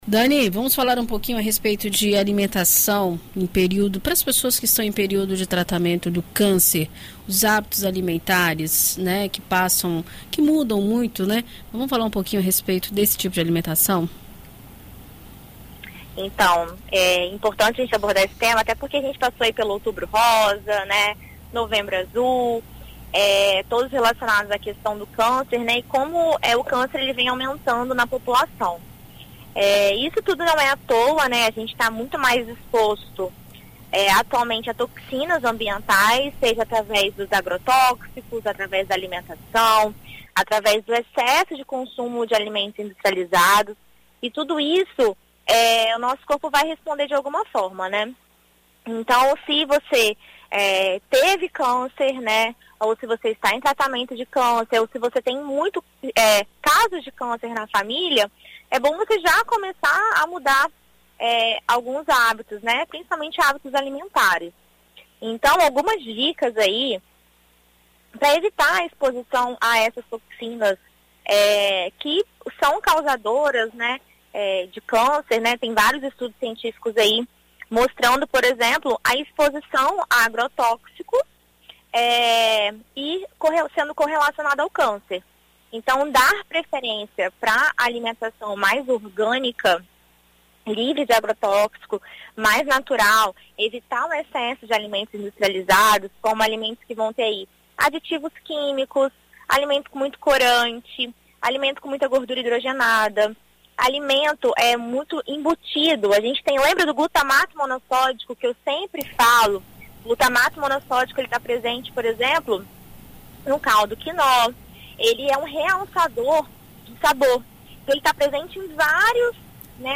Na coluna Viver Bem desta quarta-feira (14), na BandNews FM Espírito Santo